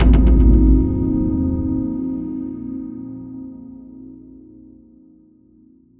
Synth Impact 13.wav